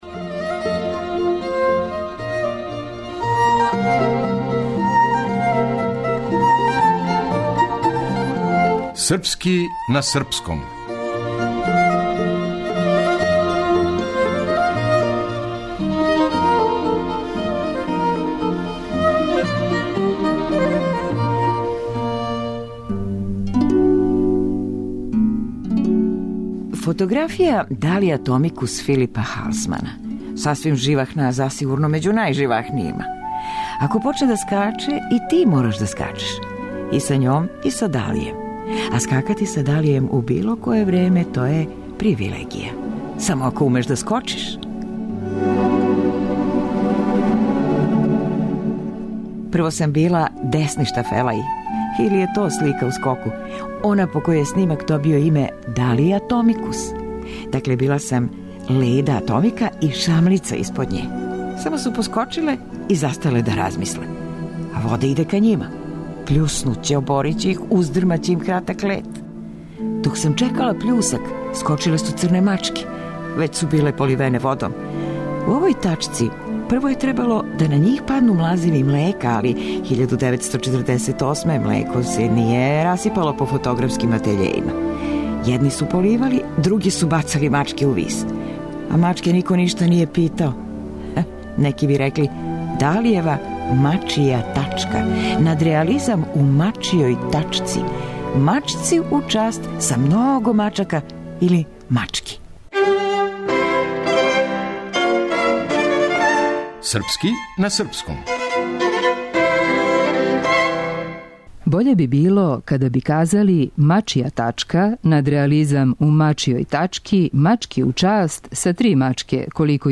Драмска уметница